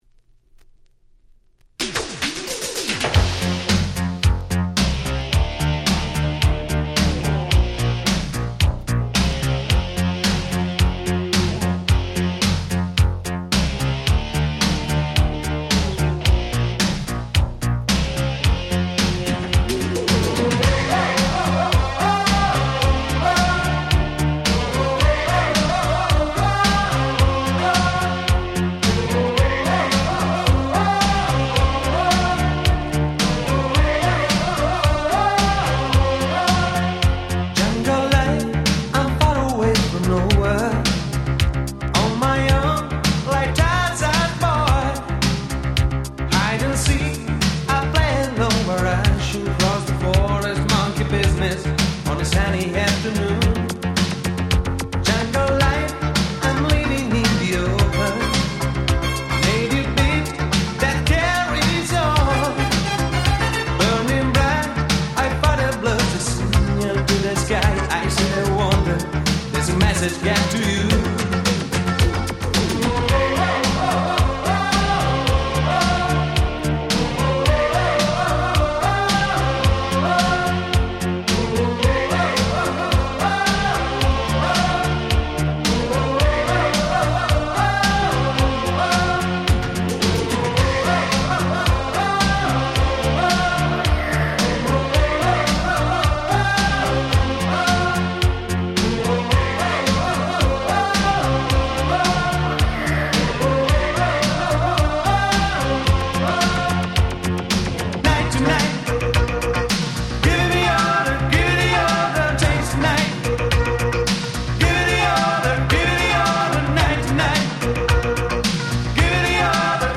84' Super Hit Itaro Disco !!